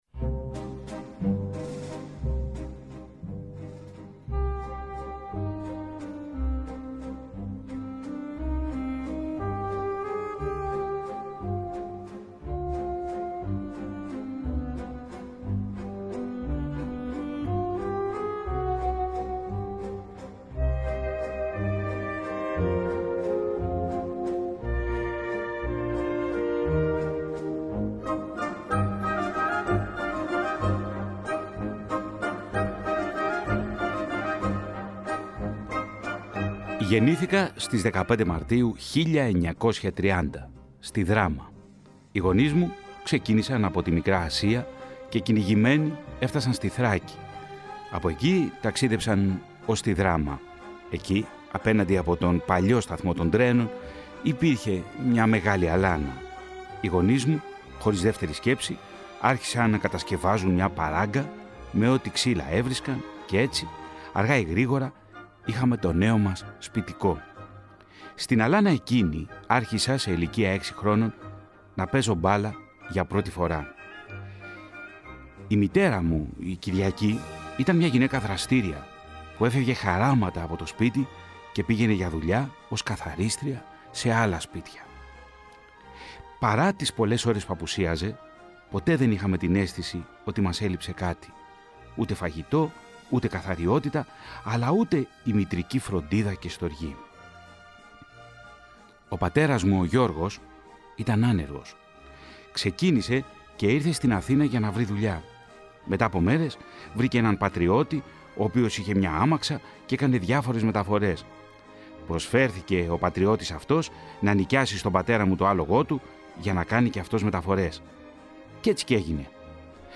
Στη θρυλική μορφή του Κώστα Νεστορίδη, που έφυγε πρόσφατα από τη ζωή, είναι αφιερωμένο το νέο επεισόδιο της ραδιοφωνικής σειράς “Στα γήπεδα της Ιστορίας”, που θα μεταδοθεί αυτή την Κυριακή 17 Δεκεμβρίου από την ΕΡΑ ΣΠΟΡ, 12 με 1 το μεσημέρι.
Το ραδιοφωνικό ντοκιμαντέρ αρχίζει από τη Δράμα, στα χρόνια του Μεσοπολέμου, εκεί που γεννήθηκε ο Κώστας Νεστορίδης και τον ακολουθεί στη λαϊκή γειτονιά της Καλλιθέας τα πρώτα μεταπολεμικά χρόνια μέχρι την ένταξή του στις γραμμές της ΑΕΚ.